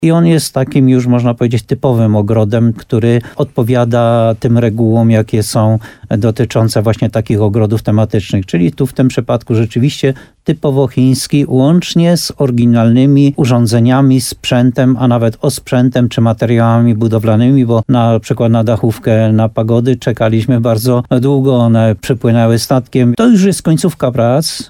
Prace są na wykończeniu – zaznaczył Jan Golba. Jeśli pogoda nie storpeduje tych planów, ogród chiński w Muszynie zostanie otwarty jeszcze w tym miesiącu.